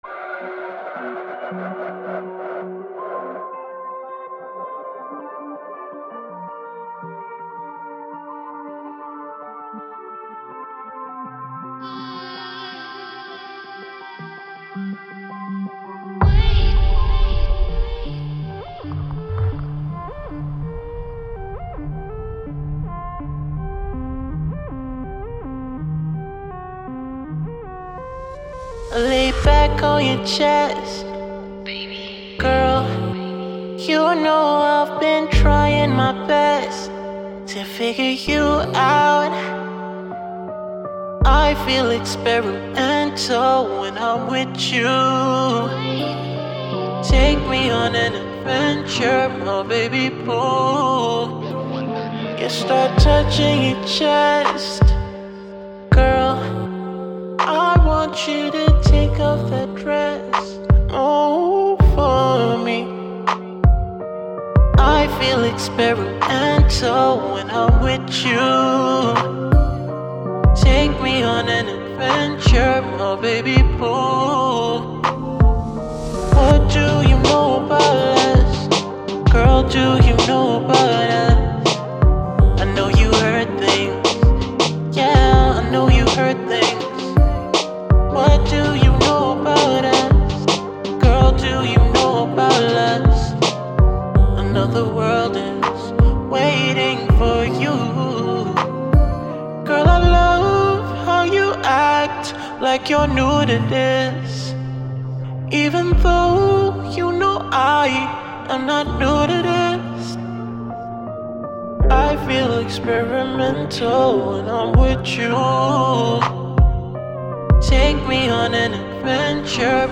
This is what I call “Trippy Blues”